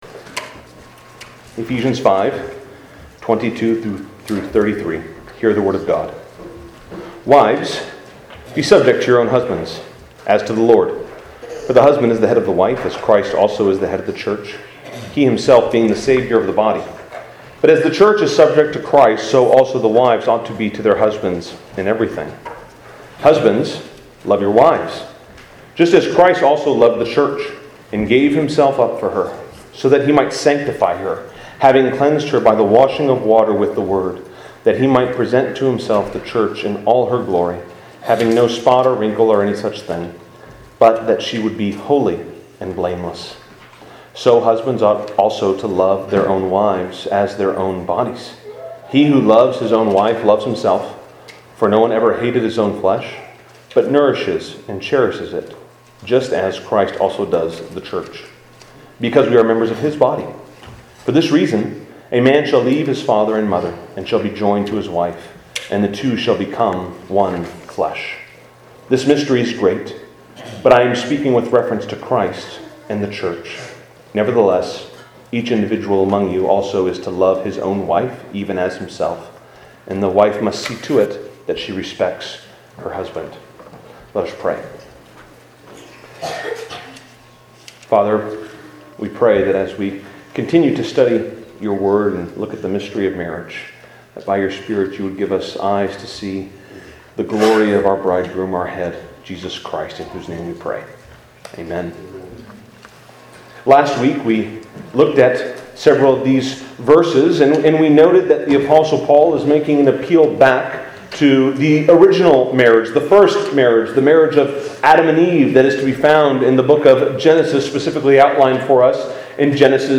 2024 The Mystery in the Nature of Marriage Preacher